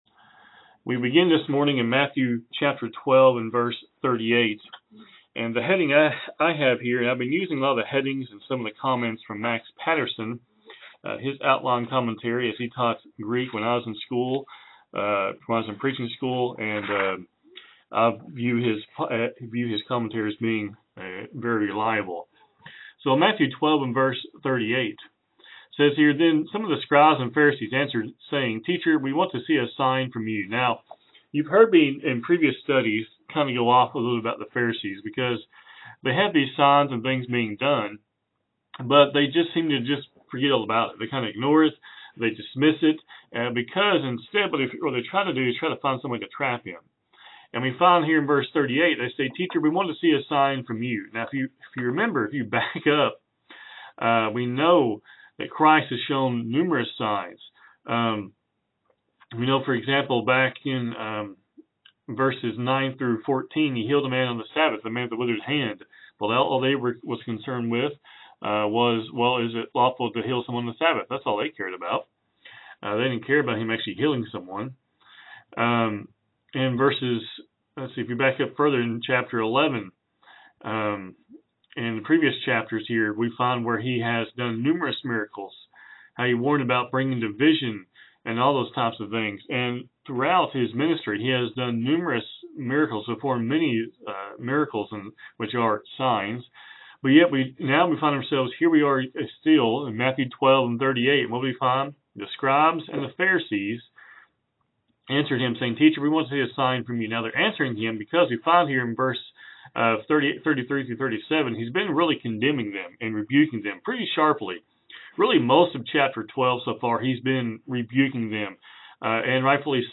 Program Info: Live program from the TGRN studio in Mount Vernon, TX